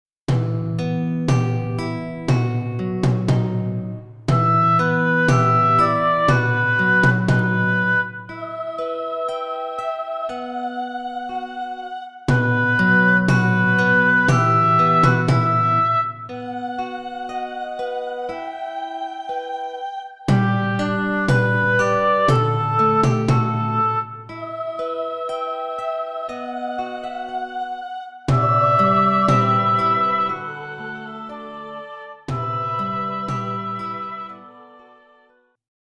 Home : Dwarsfluit :